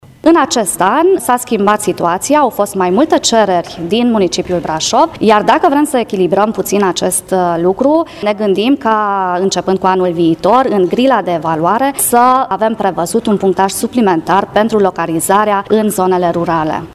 Despre o situație diferită față de anul trecut, ne-a vorvit vicepreședintele CJ Brașov, Imelda Toaso: